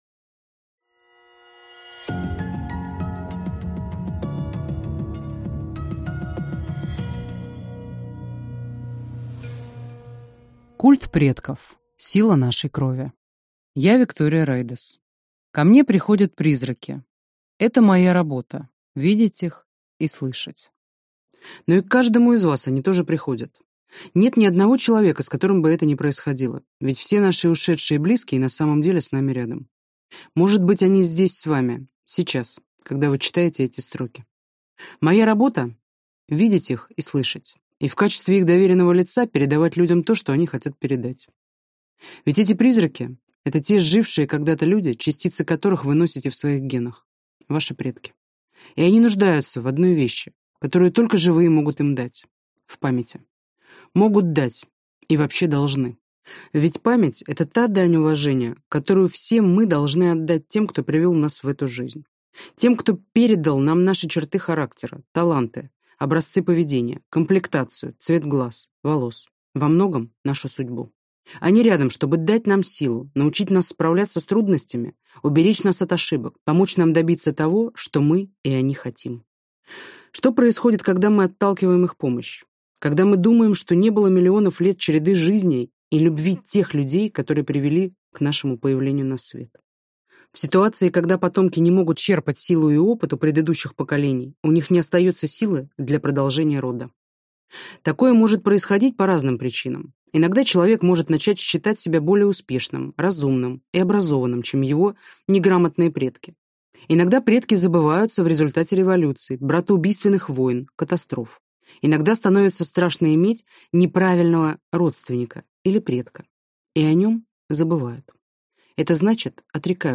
Аудиокнига Культ предков. Сила нашей крови | Библиотека аудиокниг